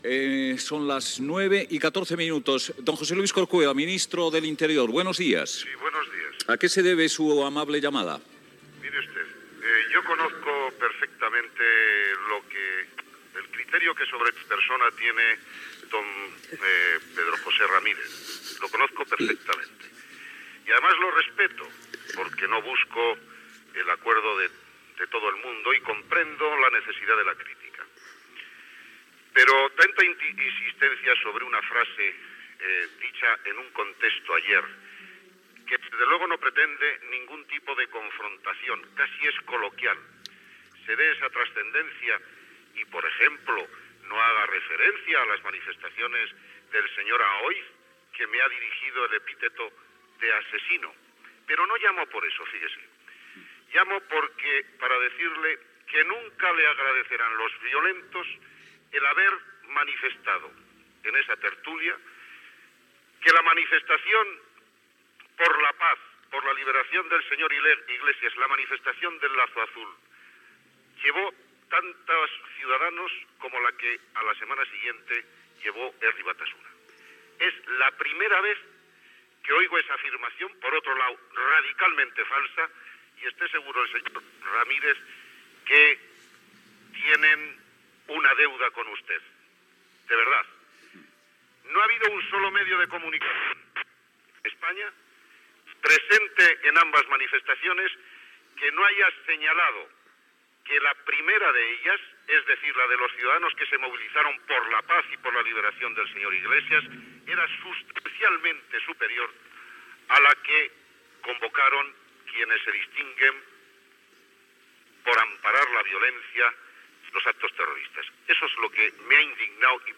Trucada del ministre de l'interior José Luis Corcuera i discussió amb el periodista Pedro J. Ramírez
Info-entreteniment